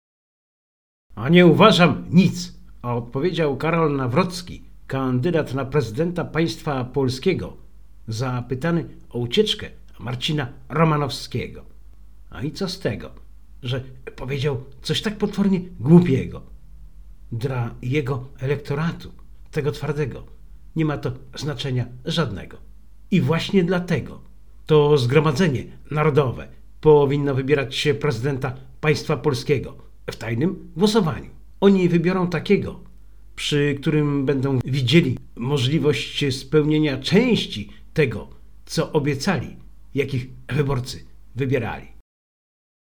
Tytuł to słowa Karola Nawrockiego, kandydata „niezależnego” na prezydenta Państwa Polskiego, które wypowiedział na konferencji prasowej.